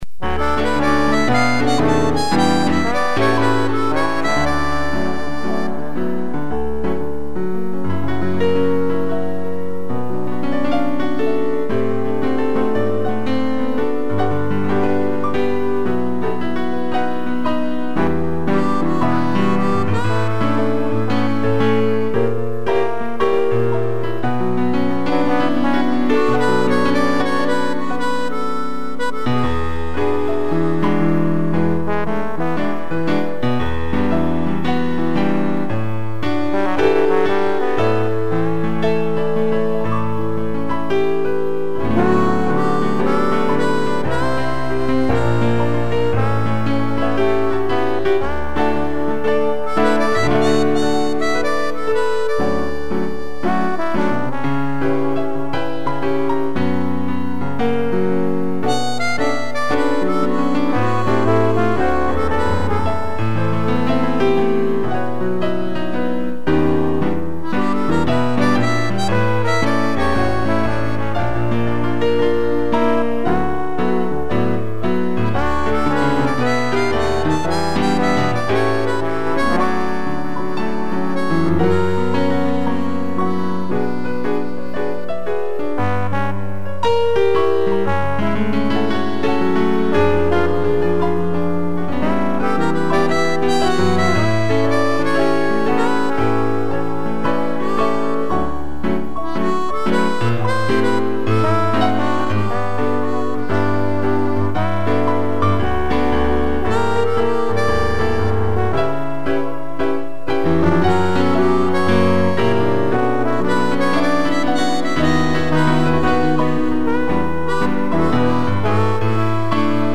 2 pianos, trombone e flauta
instrumental